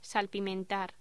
Locución: Salpimentar
locución
Sonidos: Voz humana